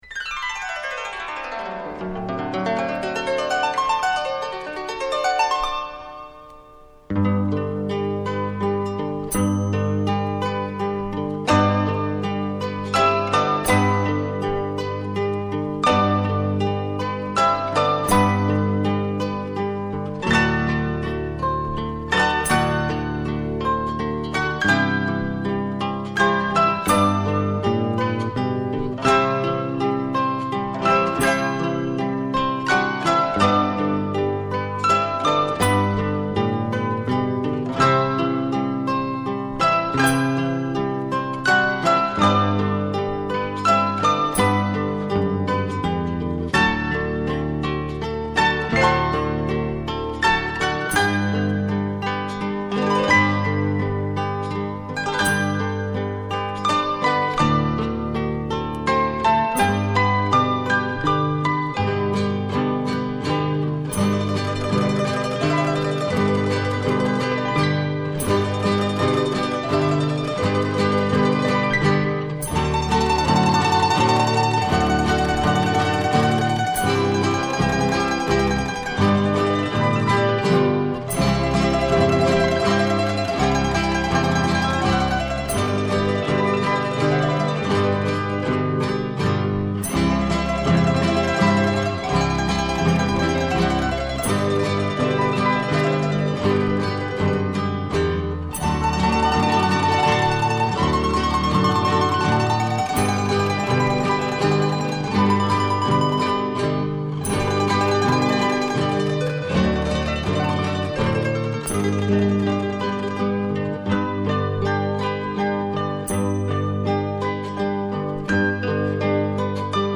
Fique com nossa mensagem musical natalina, em execução de harpas.